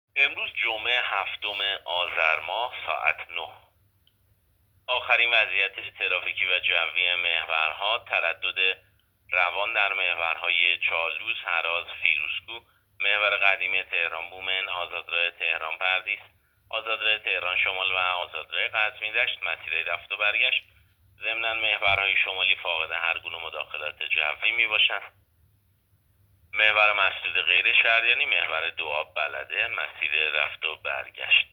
گزارش رادیو اینترنتی از آخرین وضعیت ترافیکی جاده‌ها ساعت ۹ هفتم آذر؛